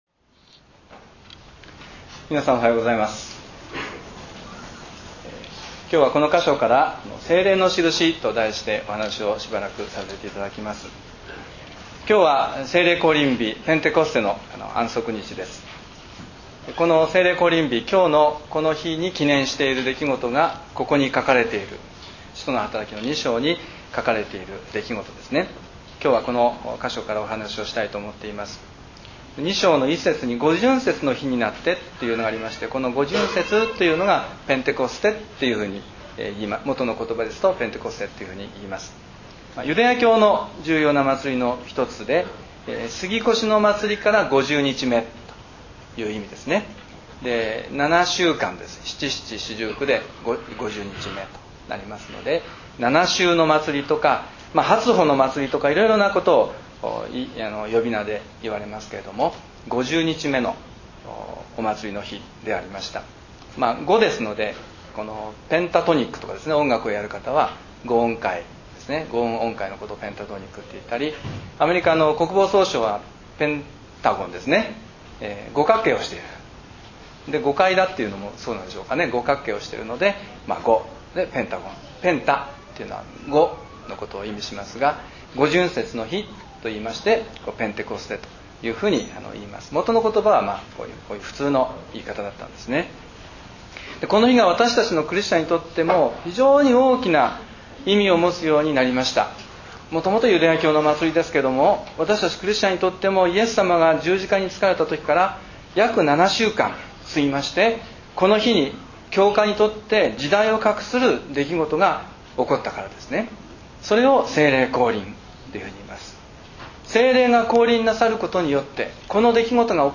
礼拝宣教録音 – 聖霊のしるし